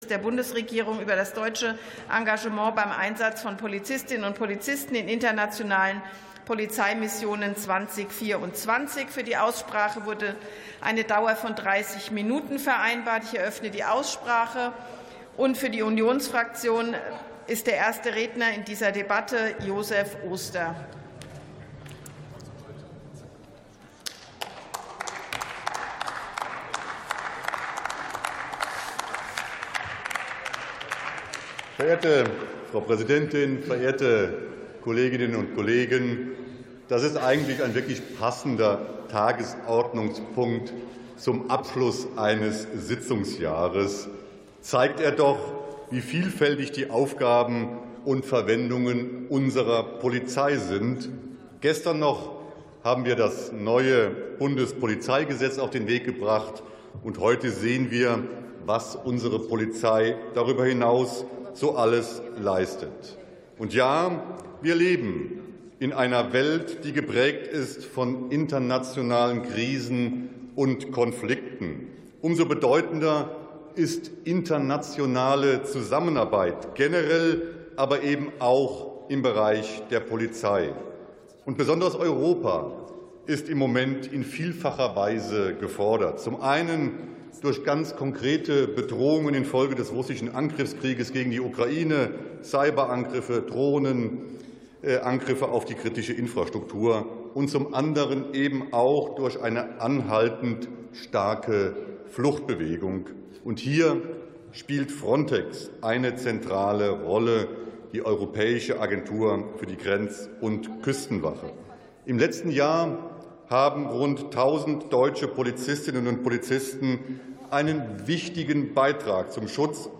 51. Sitzung vom 19.12.2025. TOP 35: Engagement in internationalen Polizeimissionen 2024 ~ Plenarsitzungen - Audio Podcasts Podcast